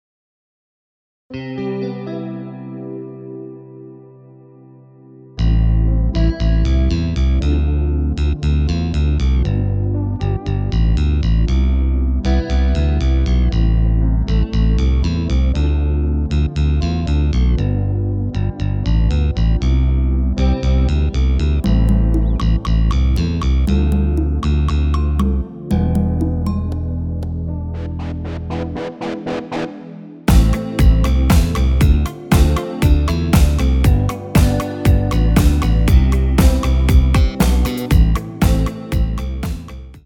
Rubrika: Pop, rock, beat